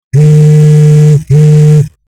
Phone Vibrating 03
Phone_vibrating_03.mp3